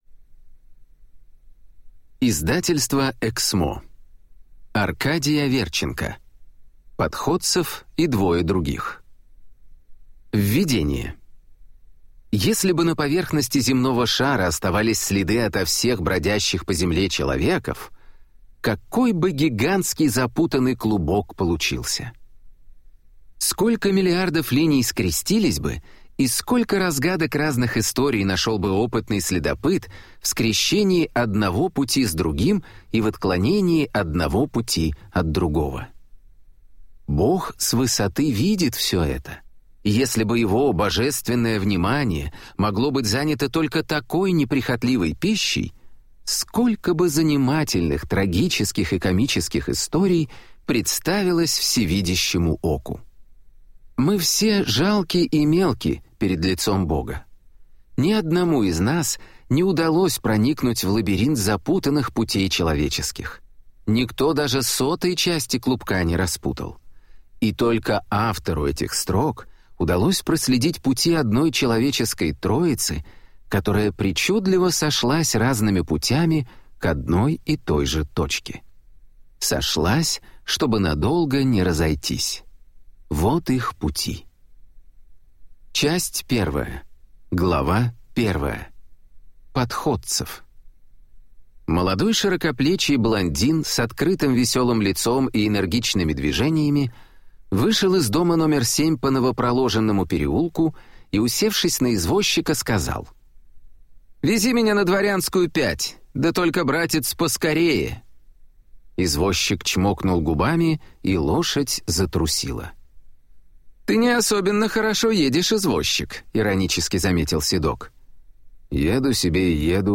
Аудиокнига Подходцев и двое других | Библиотека аудиокниг